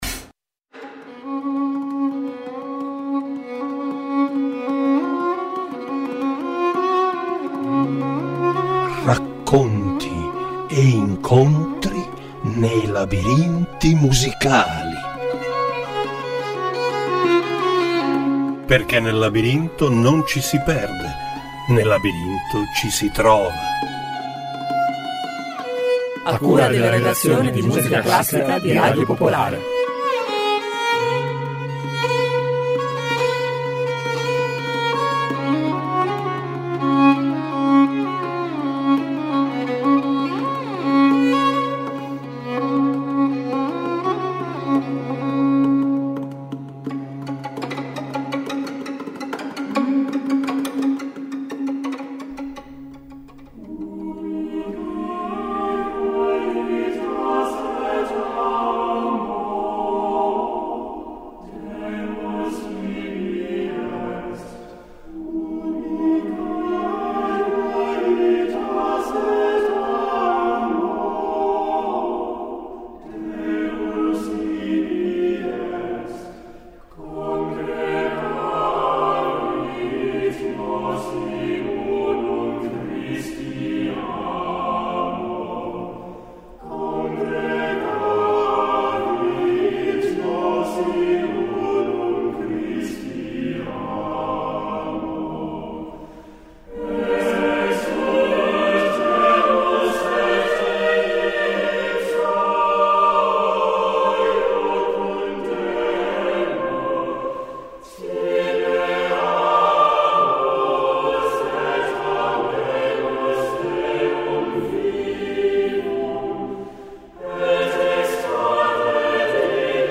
"Labirinti Musicali" ideato dalla redazione musicale classica di Radio Popolare, in ogni episodio esplora storie, aneddoti e curiosità legate alla musica attraverso racconti che intrecciano parole e ascolti.